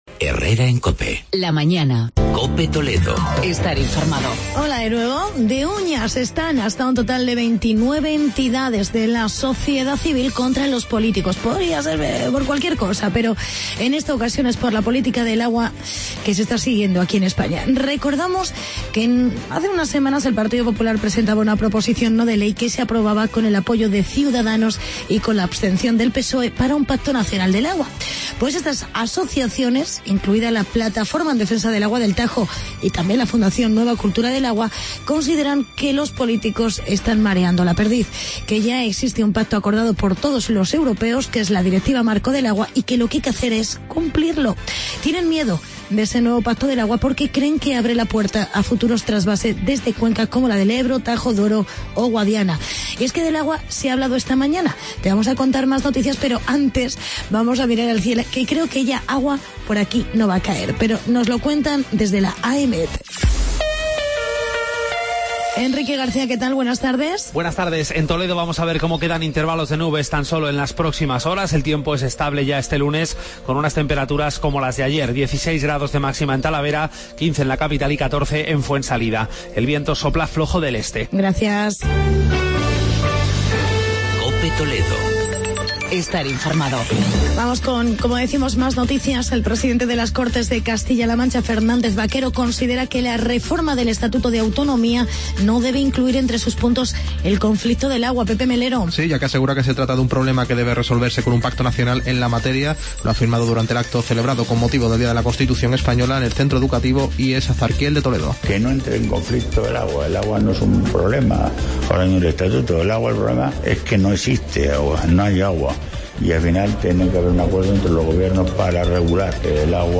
Actualidad y reportaje sobre la campaña "Tu cuentas" contra el acoso y el bullyng